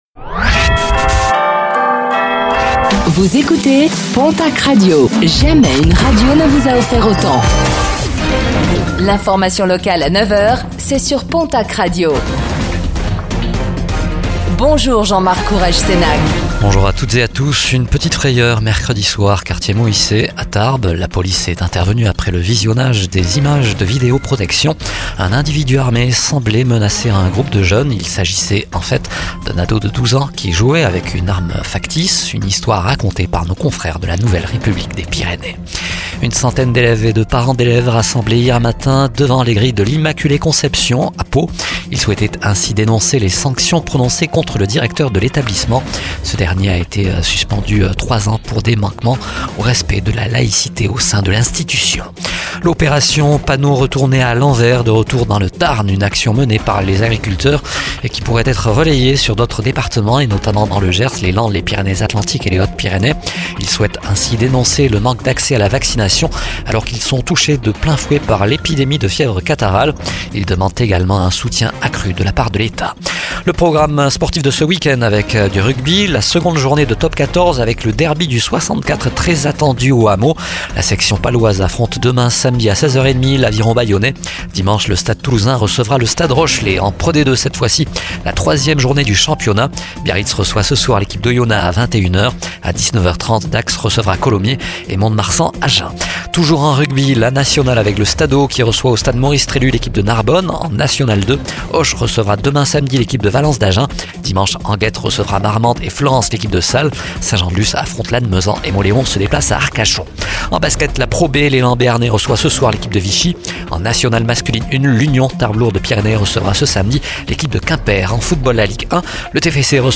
Réécoutez le flash d'information locale de ce vendredi 13 septembre 2024